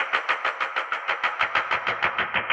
RI_DelayStack_95-05.wav